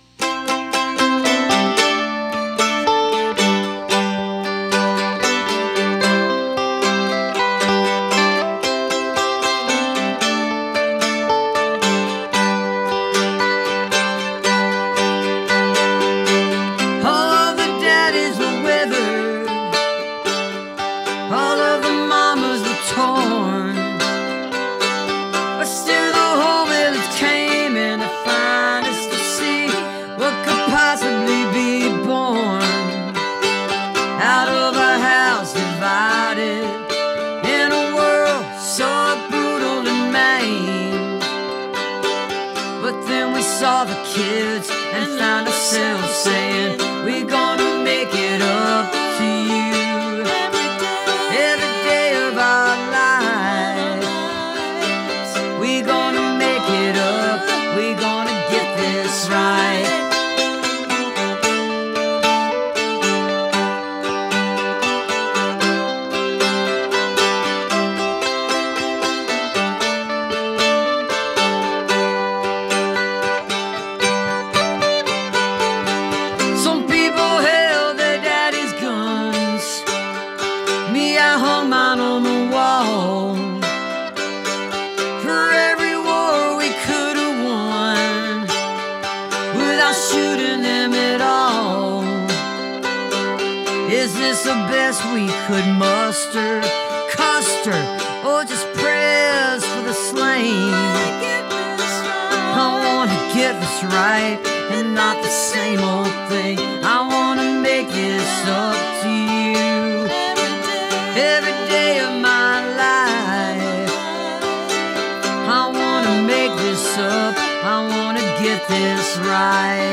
(captured from the webstream)